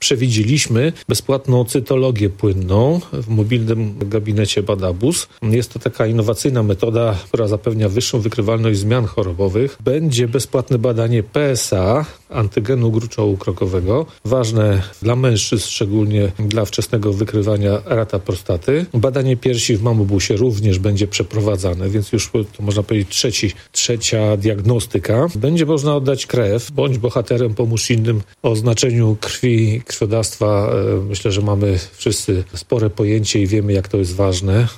Mówił Mirosław Hołubowicz, zastępca prezydenta Ełku.